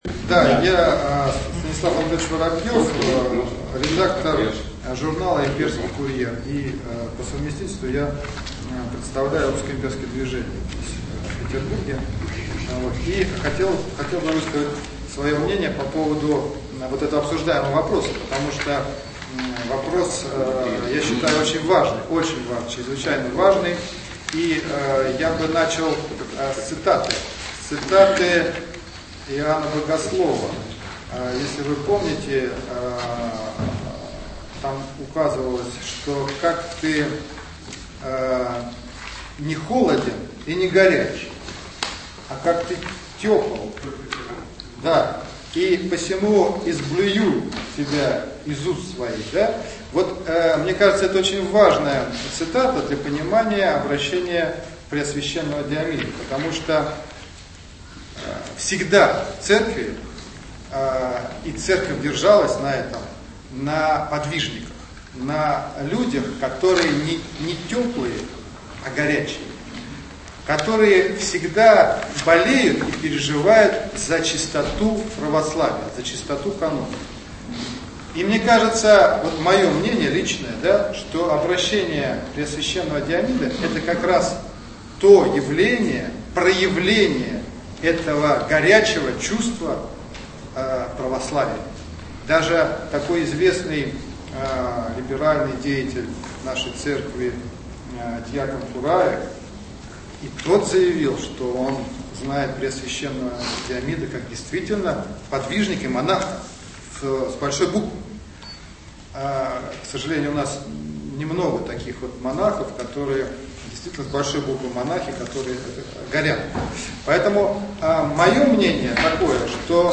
Вчера в Петербурге прошло очередное заседание Патриотического форума
Выступление